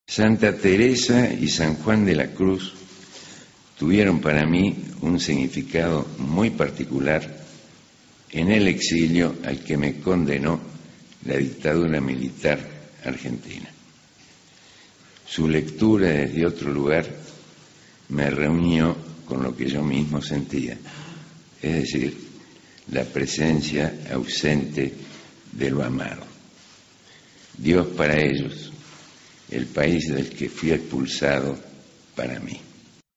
Parte del discurso del poeta Juan Gelman en el Premio Cervantes 2007 (2)